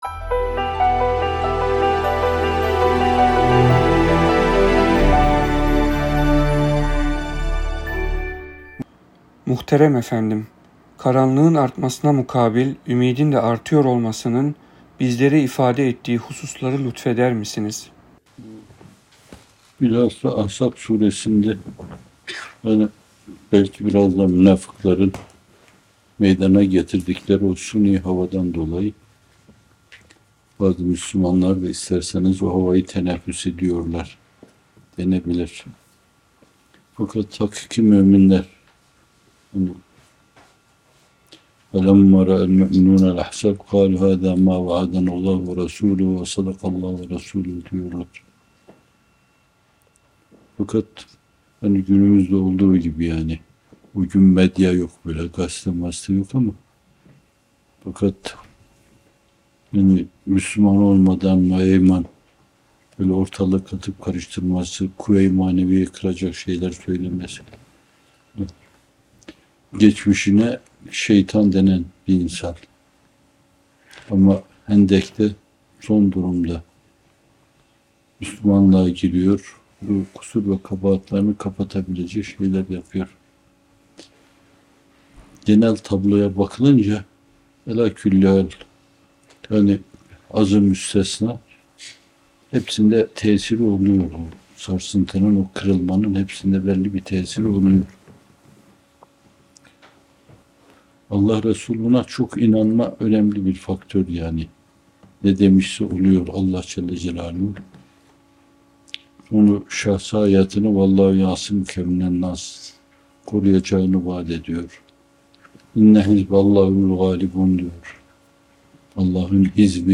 Muhterem Fethullah Gülen Hocaefendinin 16 Şubat 2017 tarihinde yapmış olduğu ilk kez yayınlanan sohbeti. Soru: Muhterem Efendim, karanlığın artmasına mukabil ümidin de artıyor olmasının bizlere ifade ettiği hususları lütfeder misiniz?